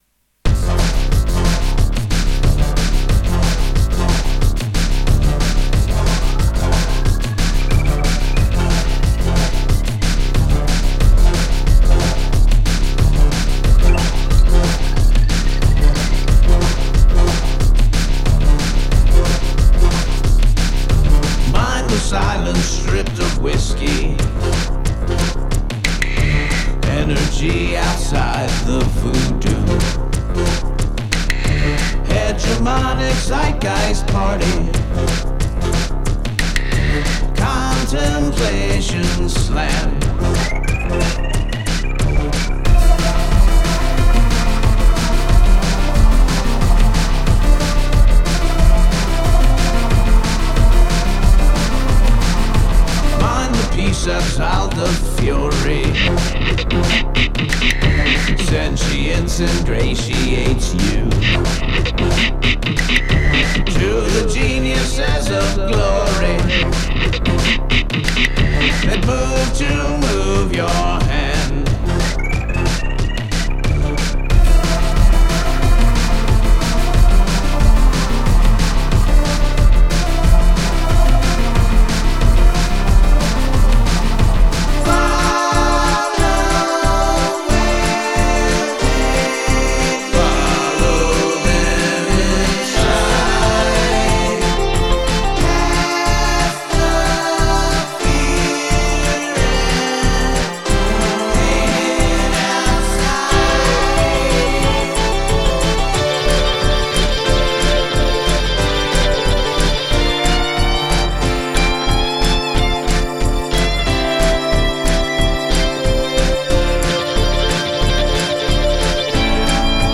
Wearing my 80s production and Bowie influence on my sleeve, I present this work.